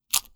Close Combat Sounds Effects
Close Combat Break Bone 12.wav